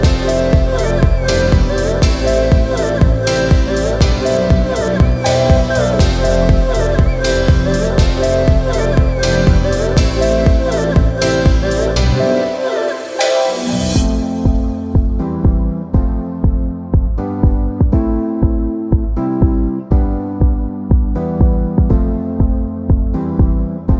For Solo Female Pop (2010s) 4:45 Buy £1.50